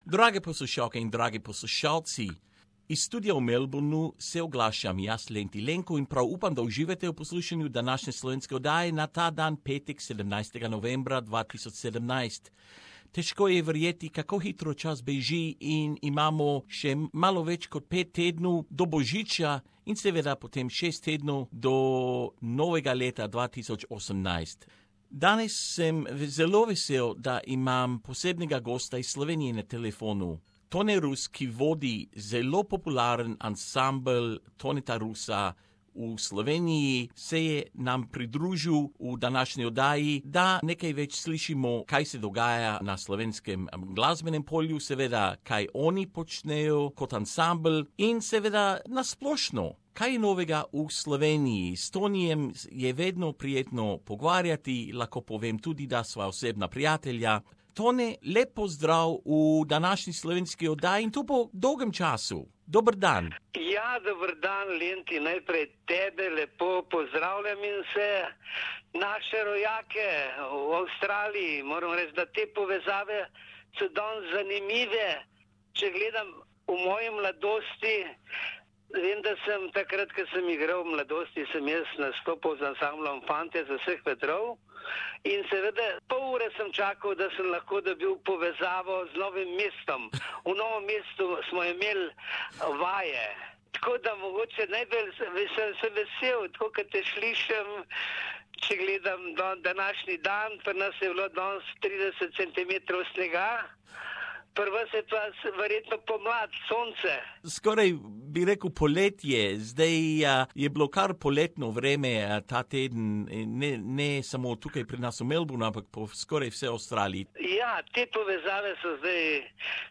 joins us for a chat.